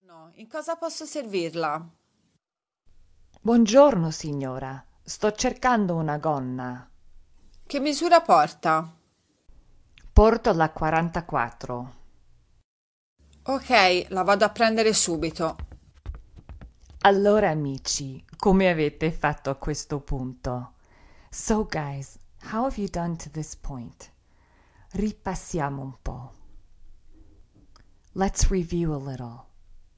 Shopping Conversation in Florence